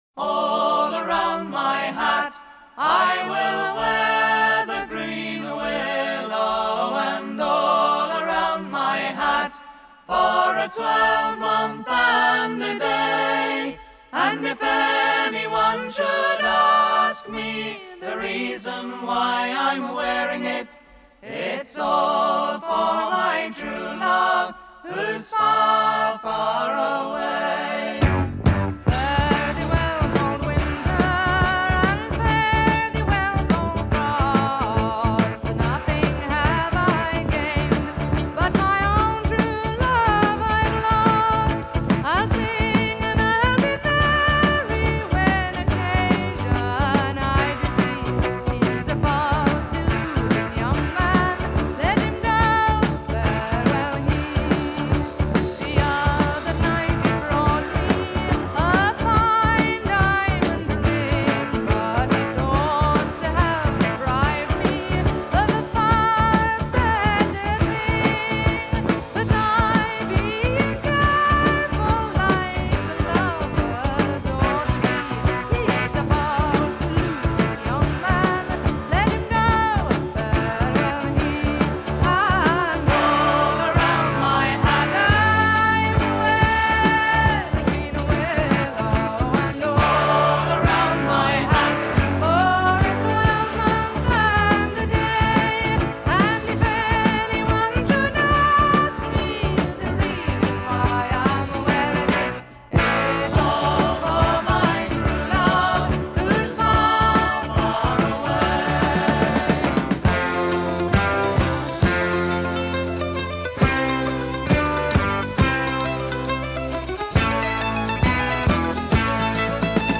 traditional folk songs with rock and blues